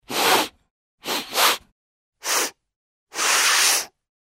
Звуки сморкания